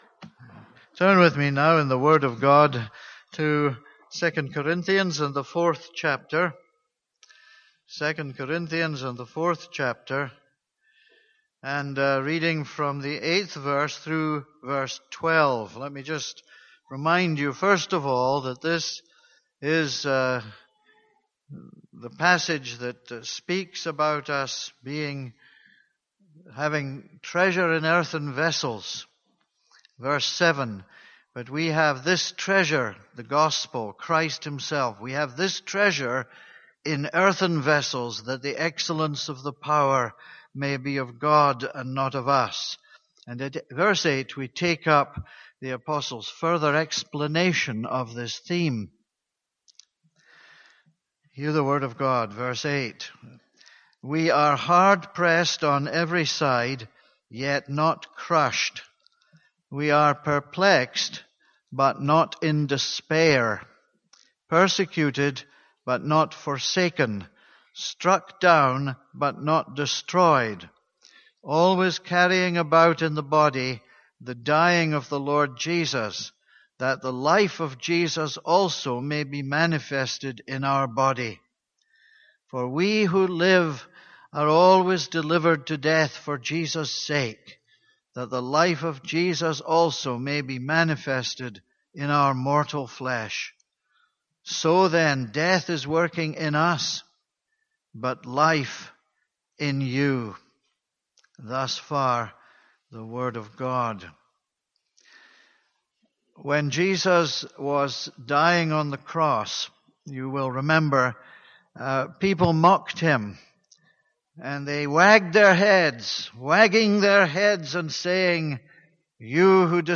This is a sermon on 2 Corinthians 4:8-12.